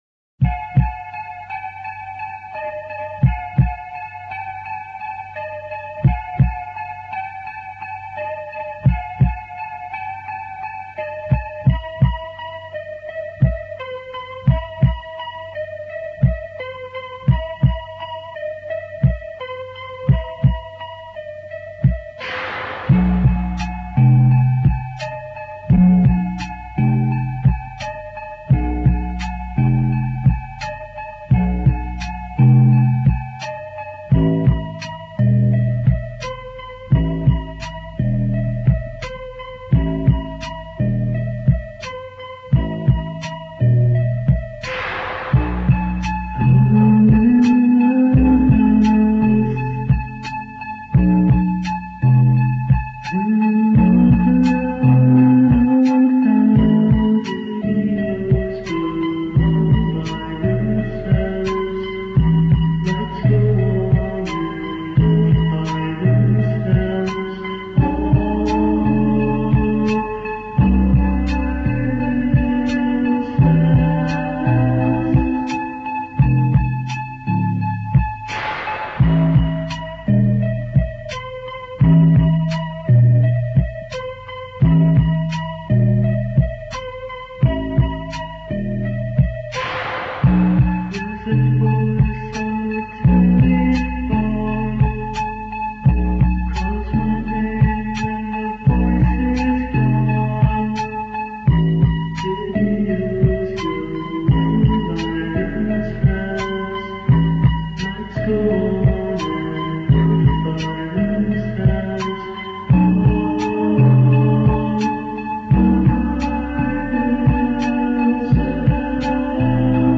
Unreleased '83 Demo